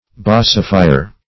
basifier - definition of basifier - synonyms, pronunciation, spelling from Free Dictionary
\Ba"si*fi`er\